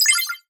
Menu Selection Button.wav